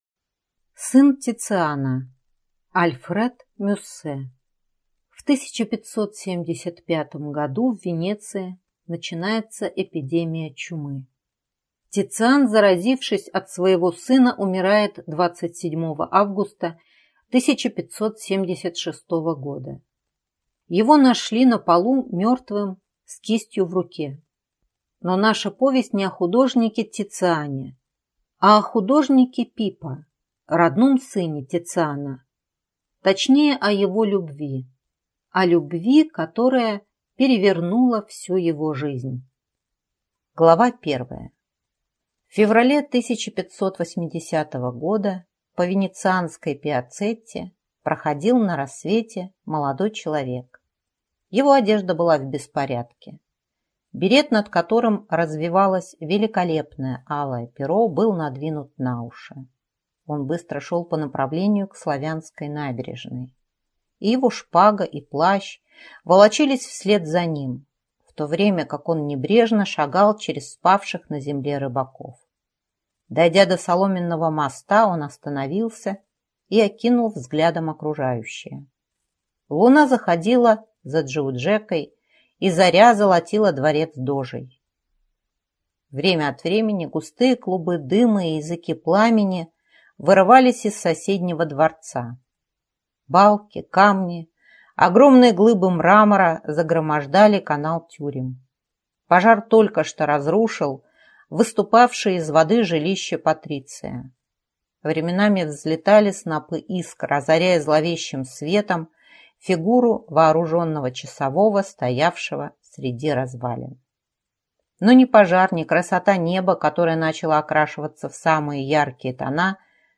Аудиокнига Сын Тициана | Библиотека аудиокниг